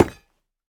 Minecraft Version Minecraft Version 25w18a Latest Release | Latest Snapshot 25w18a / assets / minecraft / sounds / block / netherite / step4.ogg Compare With Compare With Latest Release | Latest Snapshot
step4.ogg